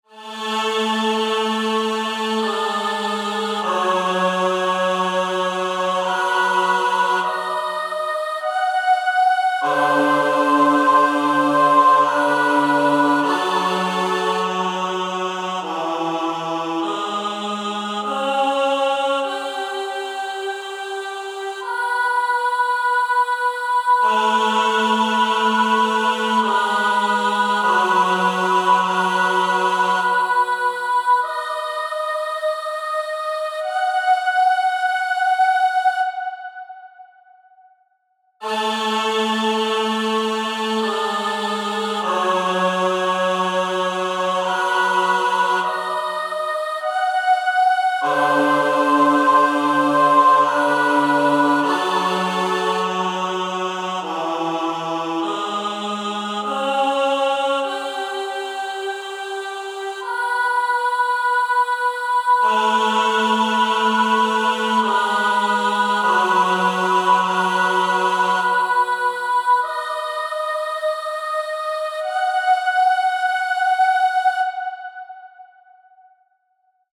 Game Music
spiritual